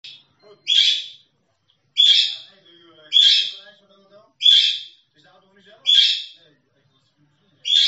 Dit. Al 2 uur lang met 3x heeeel eventjes een pauze van een paar seconden tussendoor in het echt is het ook nog 3x zo hard, sta hier in de keuken terwijl meneer in de kamer staat